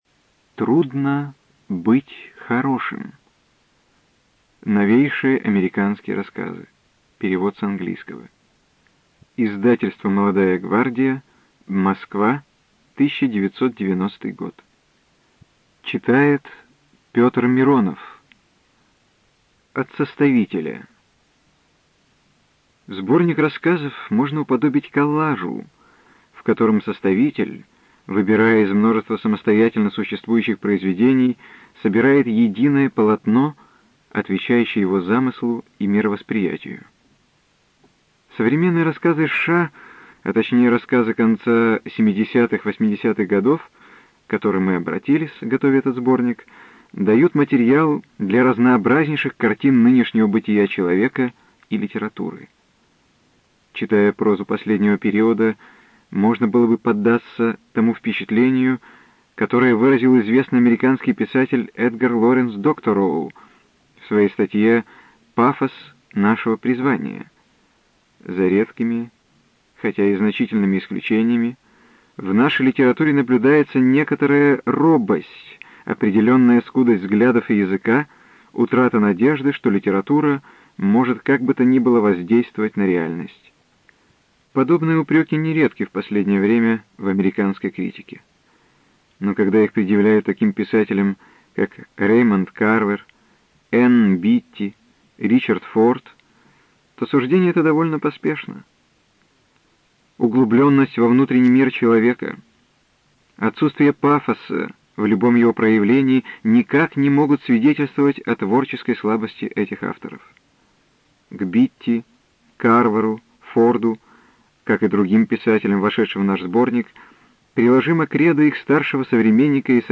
ЖанрСовременная проза
Студия звукозаписиРеспубликанский дом звукозаписи и печати УТОС